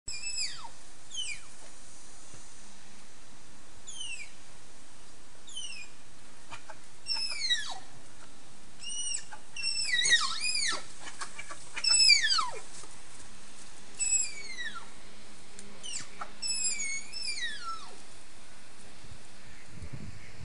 На этой странице собраны разнообразные звуки тапиров — от нежного похрюкивания до громкого рычания.
Звук малайского чепрачного тапира